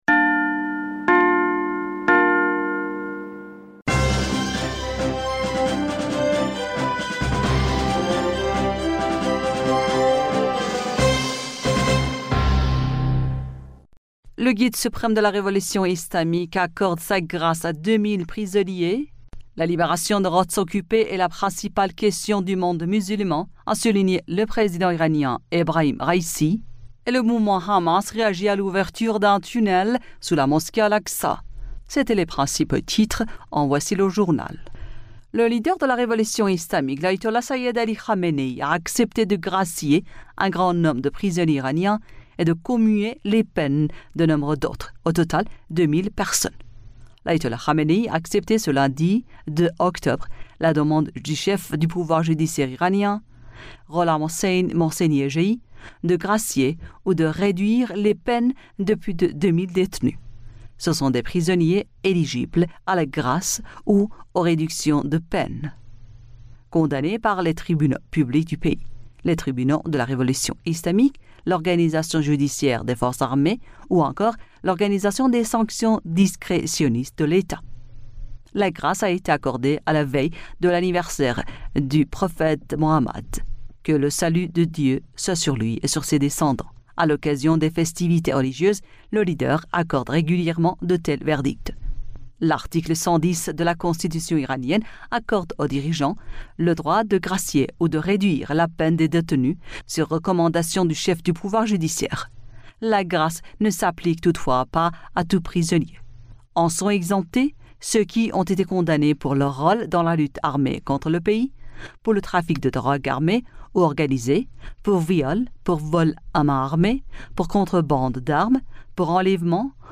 Bulletin d'information du 02 Octobre 2023